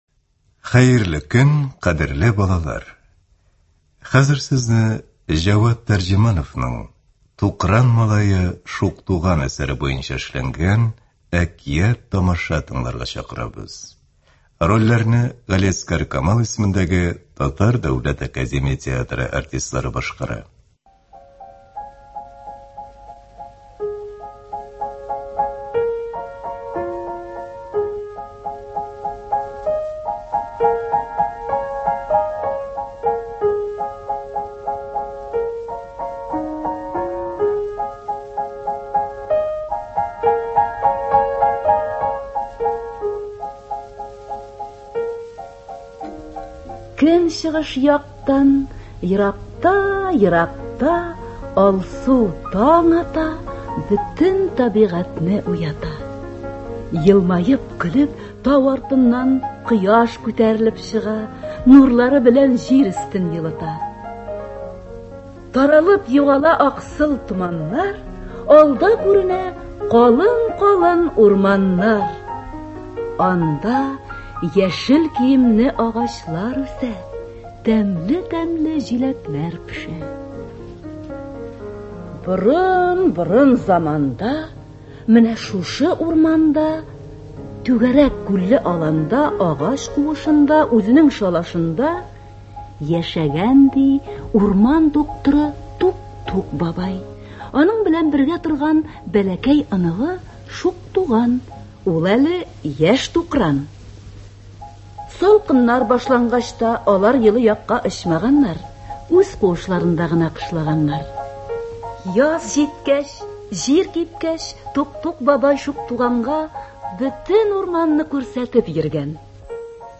Әкият-тамаша.